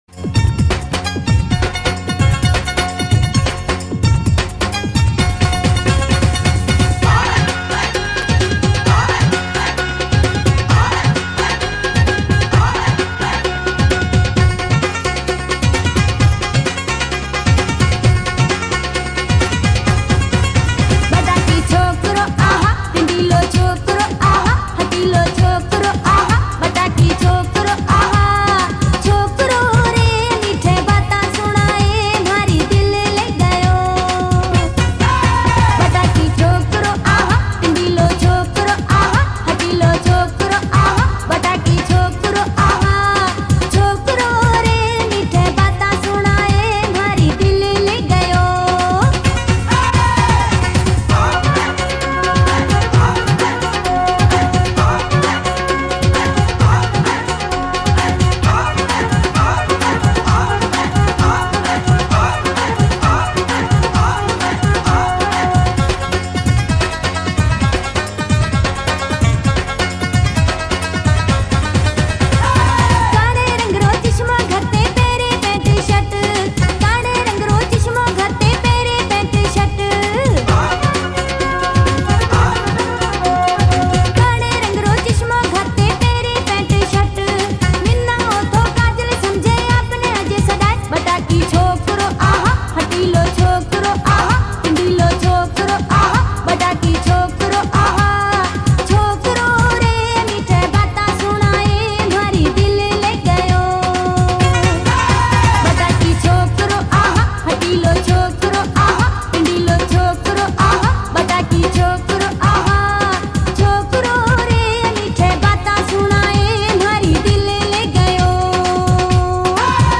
Marwari Song